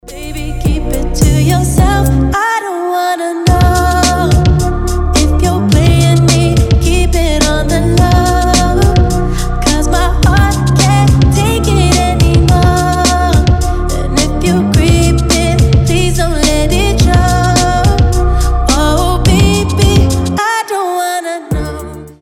• Качество: 320, Stereo
красивый вокал
ремиксы